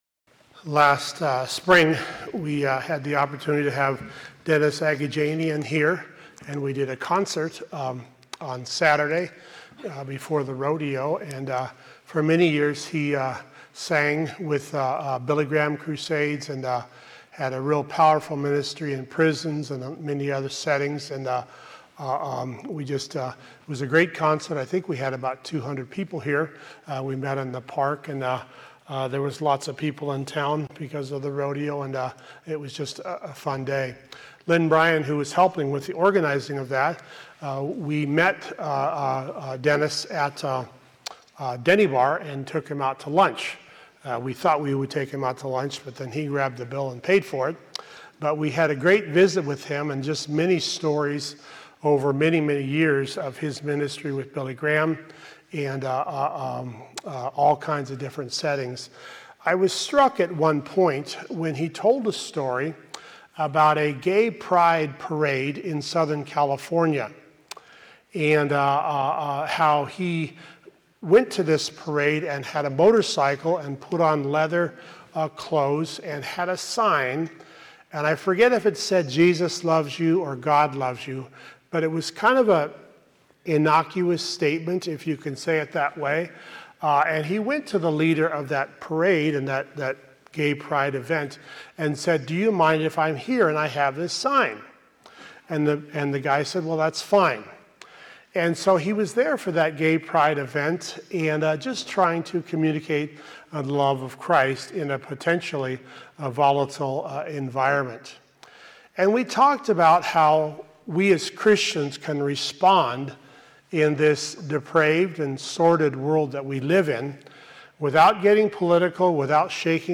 Teaching from Hebrews 12:14-17